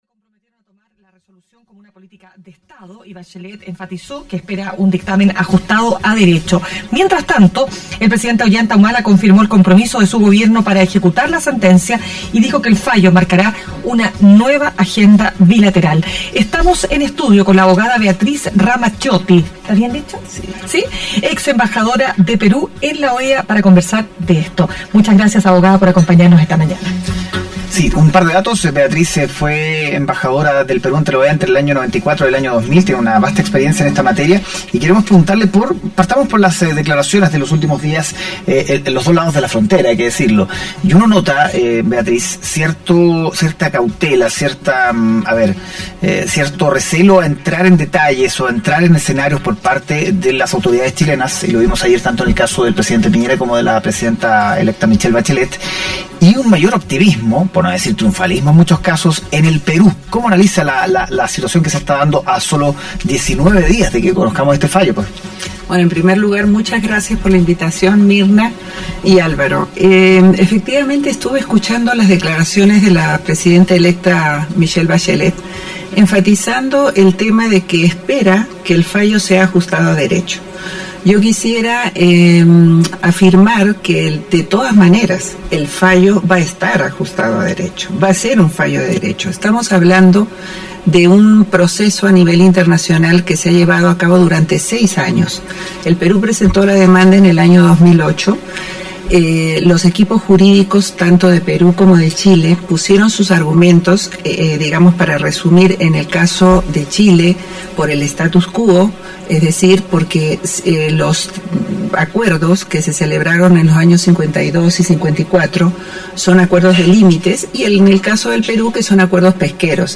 Este miércoles en Mañana Será Otro Día, conversamos con Beatriz Ramacciotti, abogada y ex embajadora de Perú en la OEA.